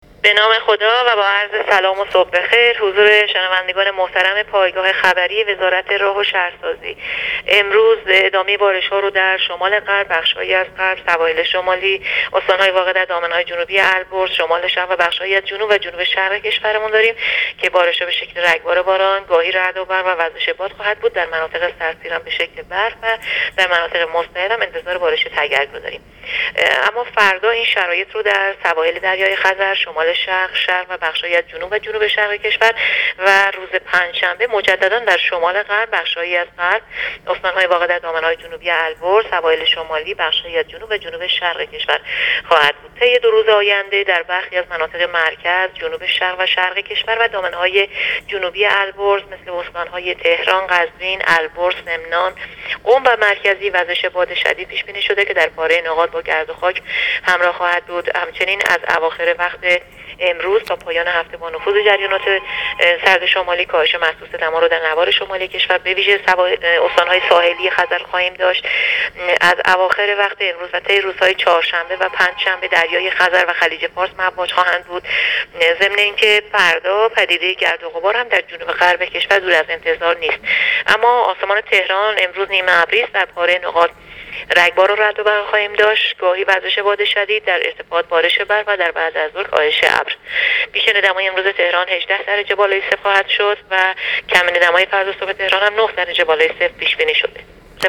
دریافت فایل weather sob با حجم 2 MB برچسب‌ها: آب و هوا - پیش‌بینی - وضعیت جوی سازمان هواشناسی هواشناسی - گزارش هواشناسی - پیش‌بینی رادیو تلویزیون نوروزی شاد، با سفر ایمن - نوروز 1395